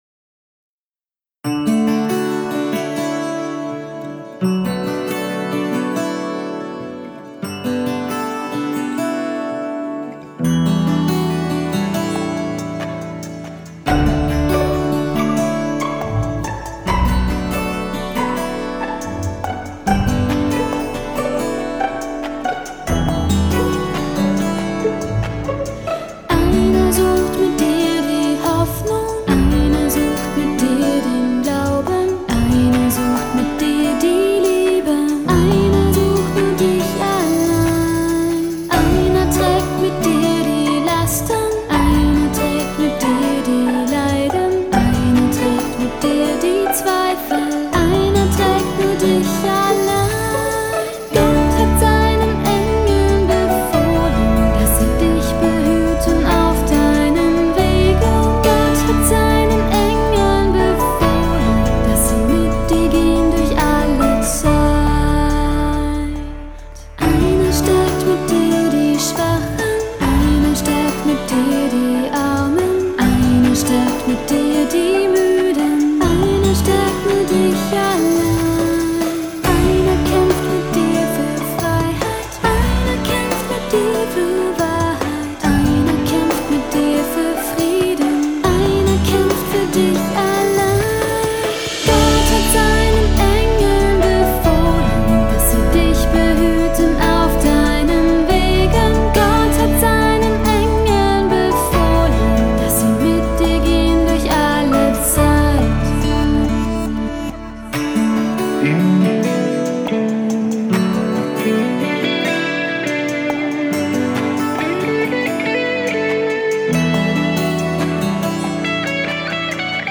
Keyboard
Vocal
Gitarren